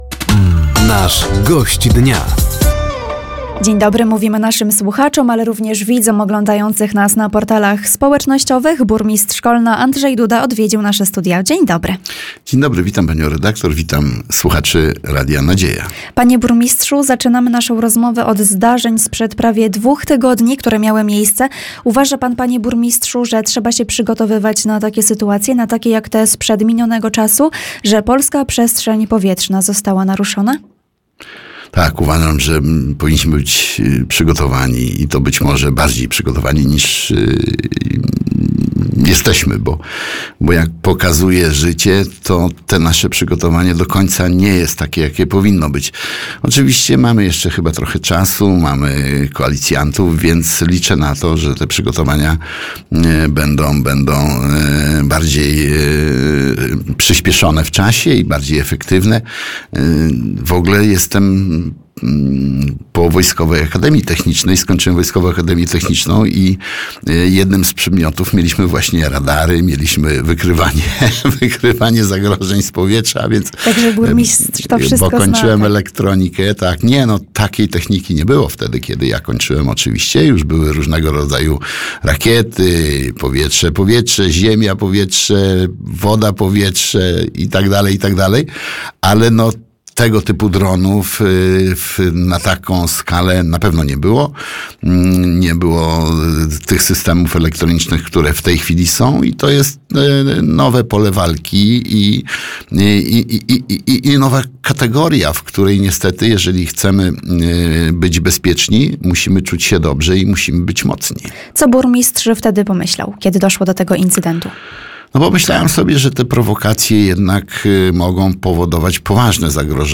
O przygotowaniu samorządów na sytuacje kryzysowe, naruszeniu polskiej przestrzeni powietrznej, a także budowie miejskiego żłobka – to główne tematy rozmowy podczas audycji ,,Gość Dnia”. Studio Radia Nadzieja odwiedził burmistrz Kolna, Andrzej Duda.